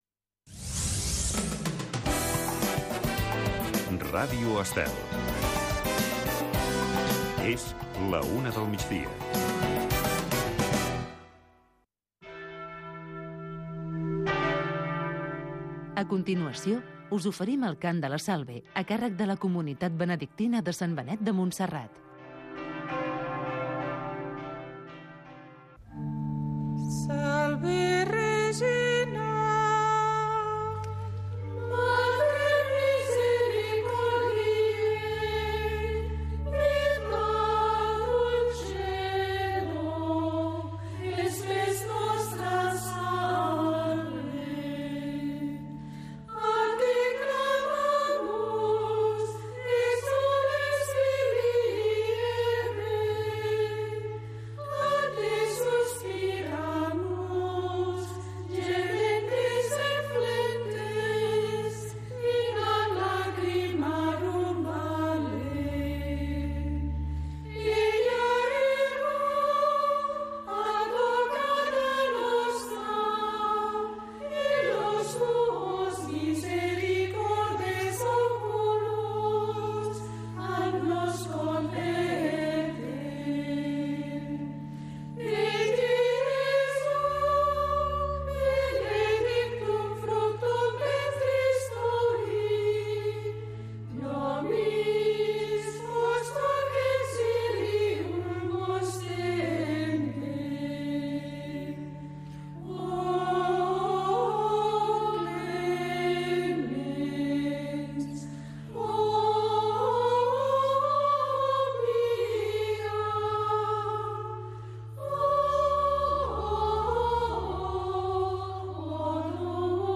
Espai amb d'entrevistes als protagonistes més destacats de cada sector, amenitzat amb la millor música dels 60, 70, 80 i 90